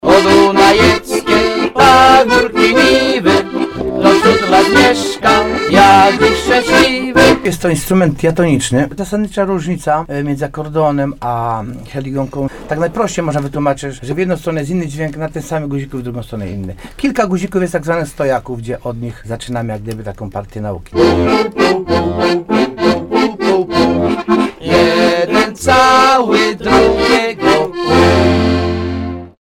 Ci gościli na antenie radia RDN Nowy Sącz, wyjaśniali podstawowe zasady ich używania.